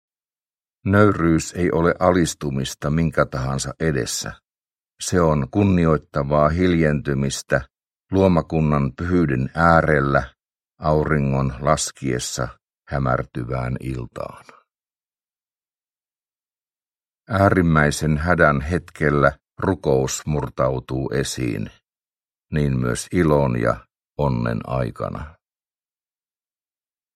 Mogadishun taksiasema – Ljudbok – Laddas ner
Uppläsare: Leo Makkonen